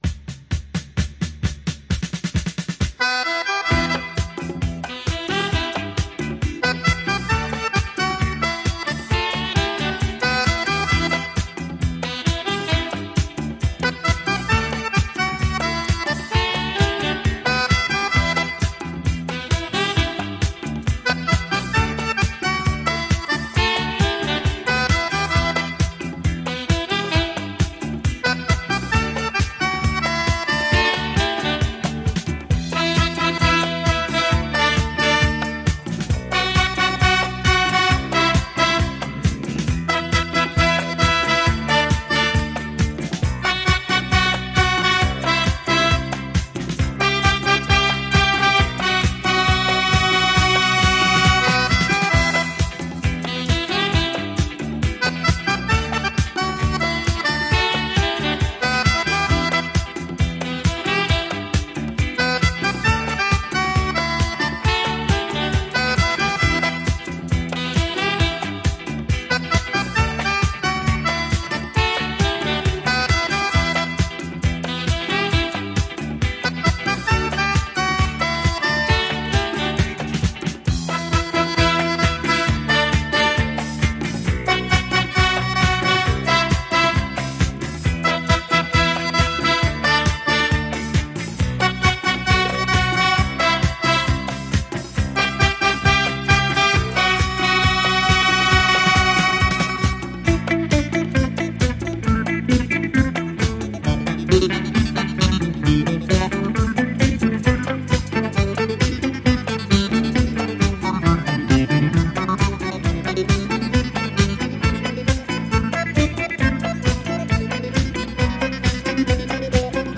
Жанр: Easy Listening, Accordeon